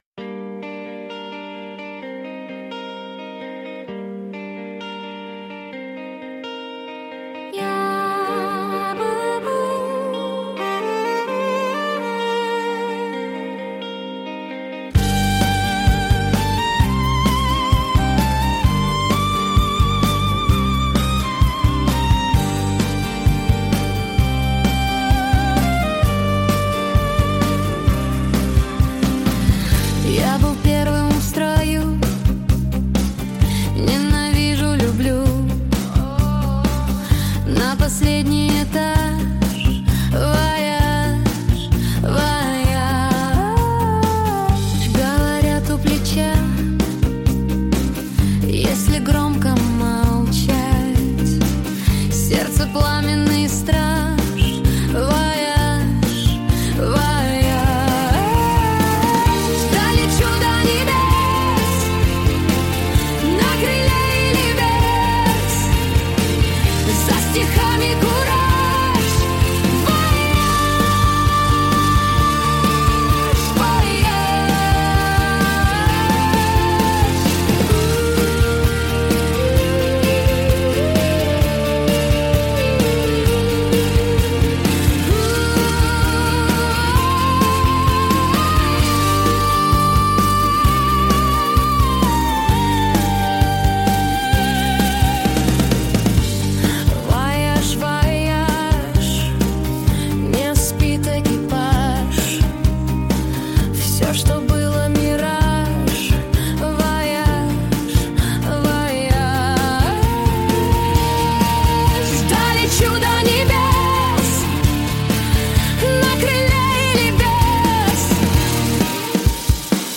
Музыка ИИ.
Только почему женским голосом?  biggrin
спасибо cool  просто женский вокал больше нравится biggrin